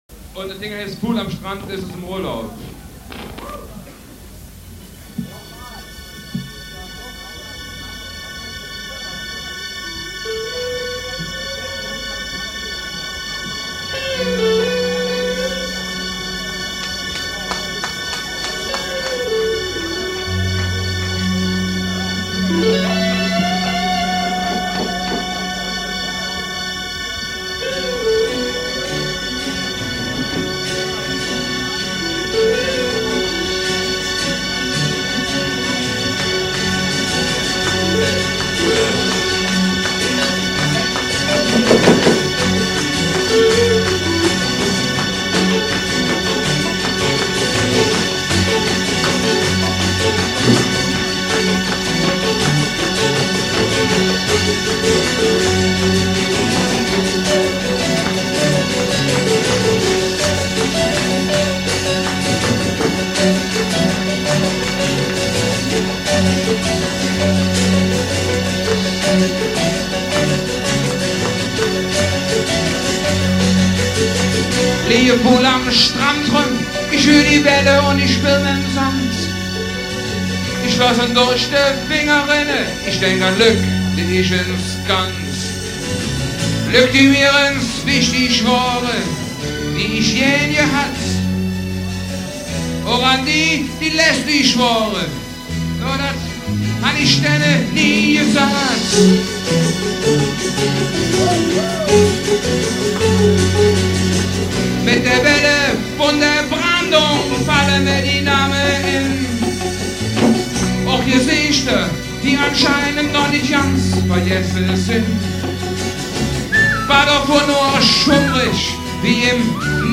Live-Mitschnitt
Dezember 1980 in den "Rheinterrassen" Bonn